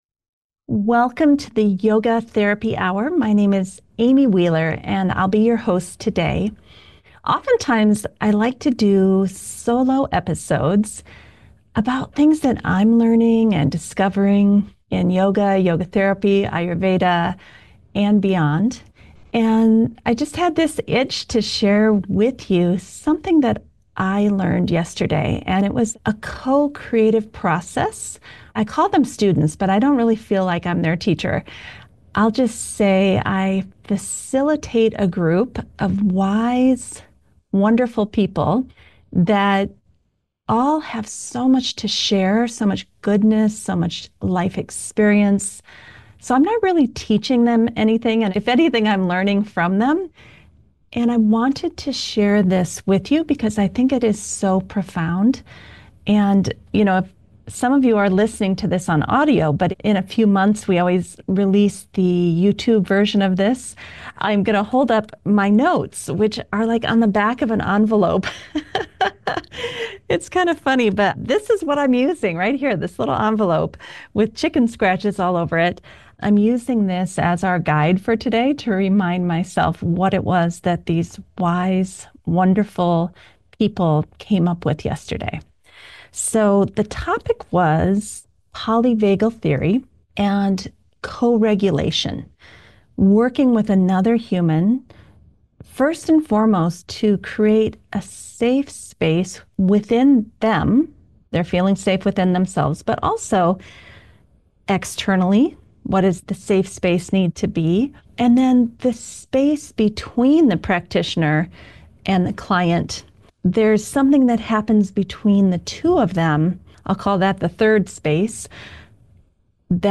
In this thought-provoking solo episode of The Yoga Therapy Hour,